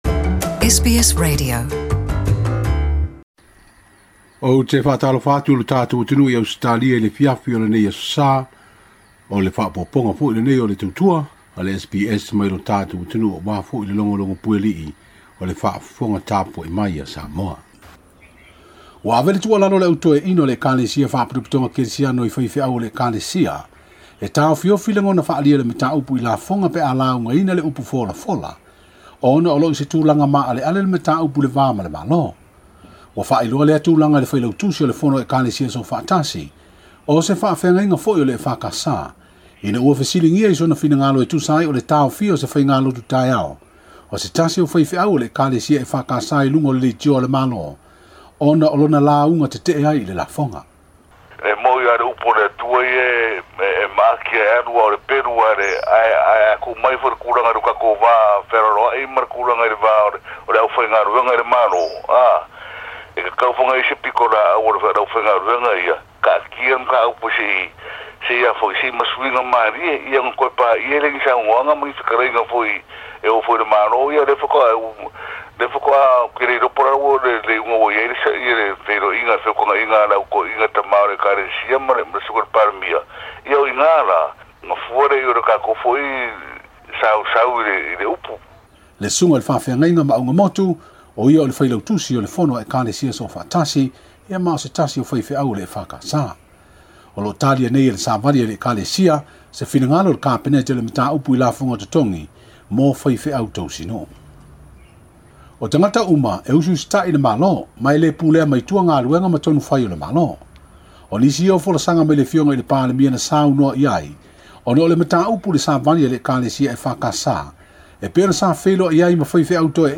Talafou o Samoa Aso Sa 24 Iuni